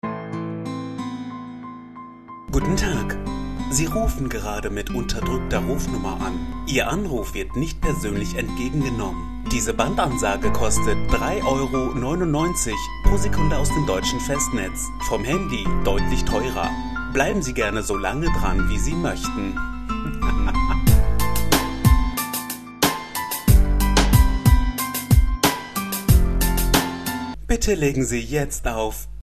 Hier unten findest du zwei Bandansagen zum kostenlosen runterladen.
Ansage_HP_CALLCENTER.mp3